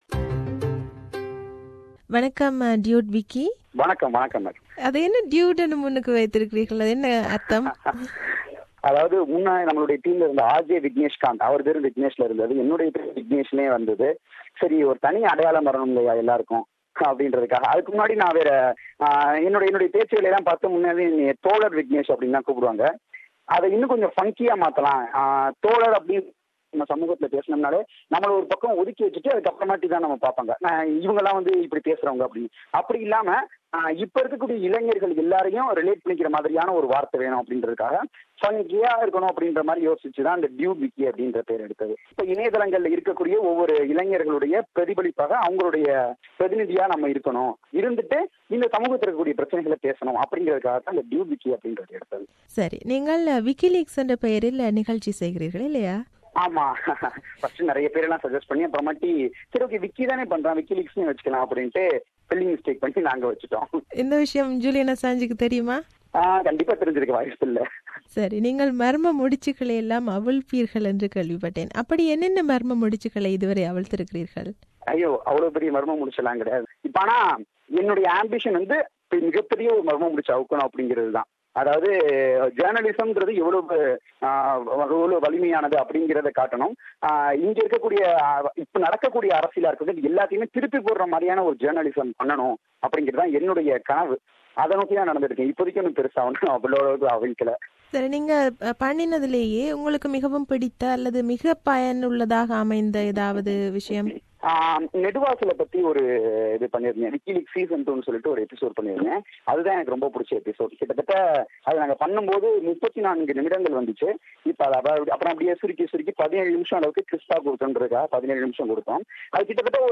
This is an interview